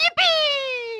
Mario Yipee - Sound-Taste
mario-yipee.mp3